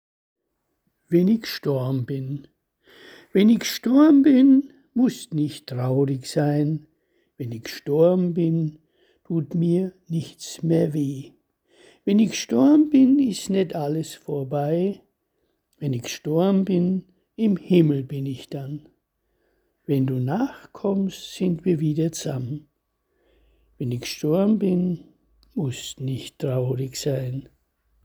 Lesung eigener Gedichte
Wenn I gstorbn bin (Lesung);